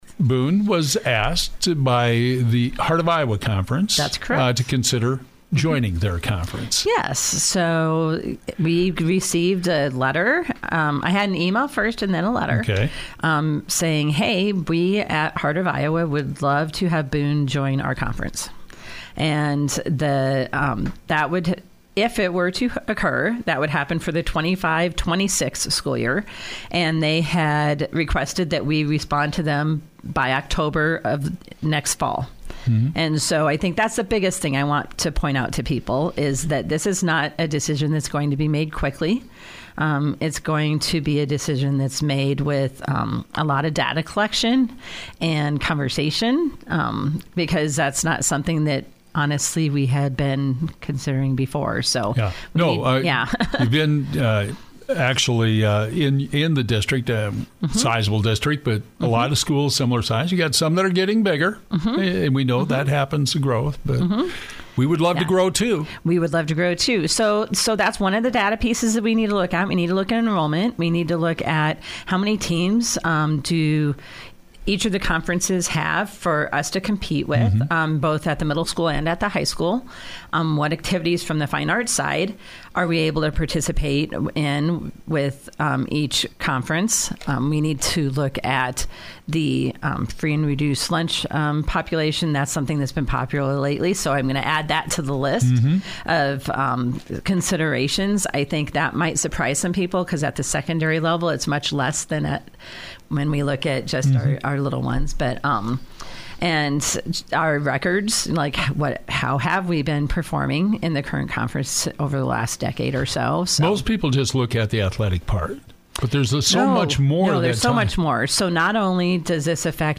FULL INTERVIEW HERE